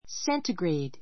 centigrade séntəɡreid セ ンティ グ レイ ド 形容詞 （温度が） セ氏の, 摂氏 せっし の ⦣ ℃ と略す. 10°C 10 ℃ （読み方: ten degrees centigrade） セ氏10度 参考 「摂氏」の「摂」はこの温度計の考案者であるスウェーデンの天文学者 A.Celsius （1701—44） の中国語表記から.